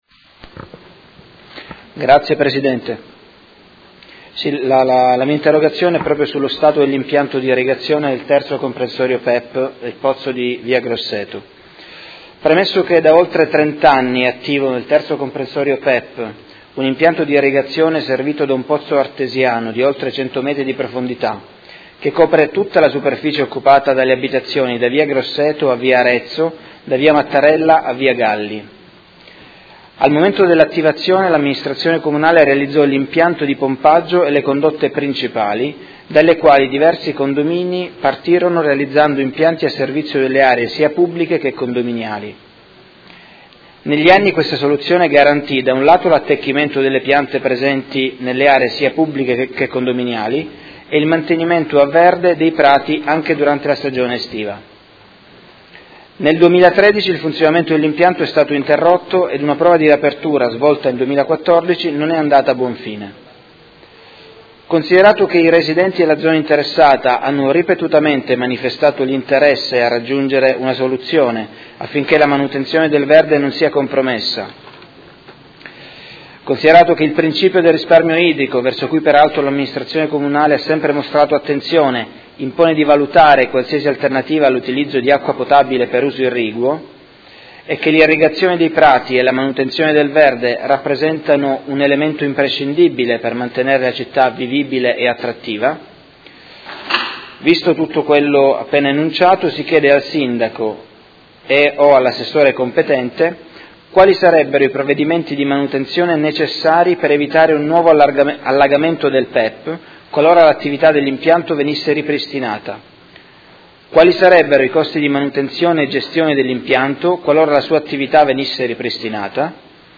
Tommaso Fasano — Sito Audio Consiglio Comunale